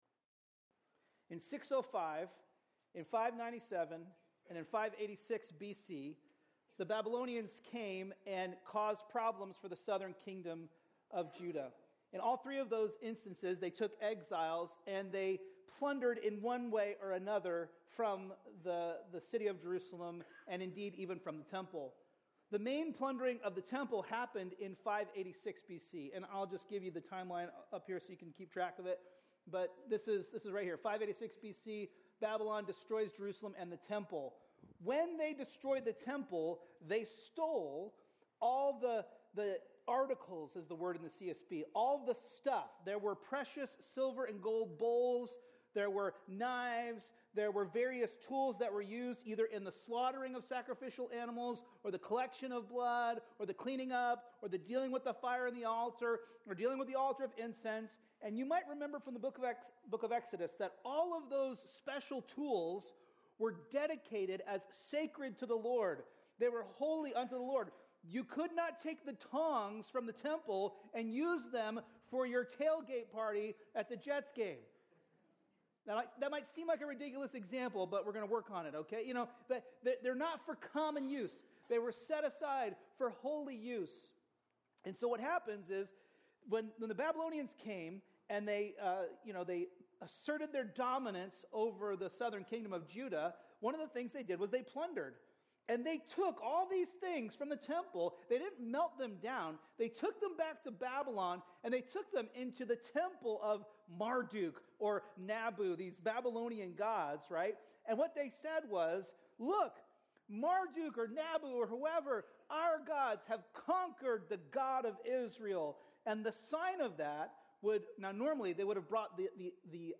A message from the series "Ezra/Nehemiah." In Nehemiah 11:1 - 12:26, we learn that God restores us to live in holiness with Him for eternity and He doesn't just call to holiness, He makes us holy through Christ.